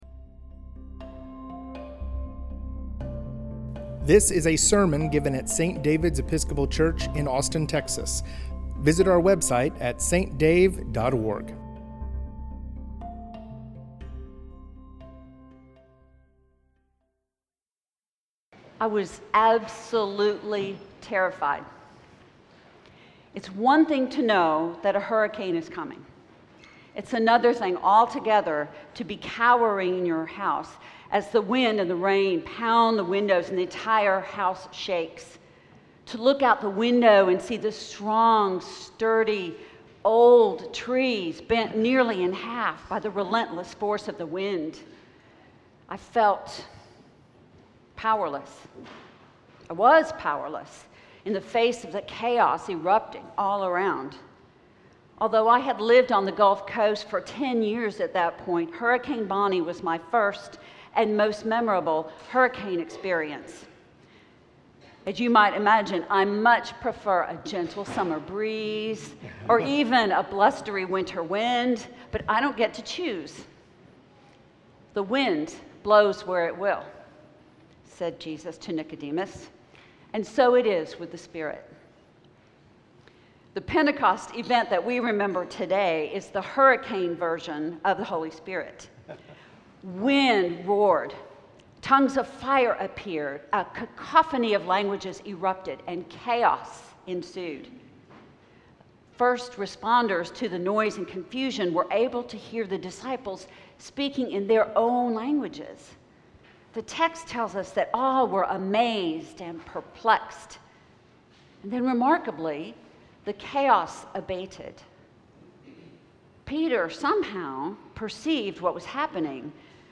The Abbey at St. David's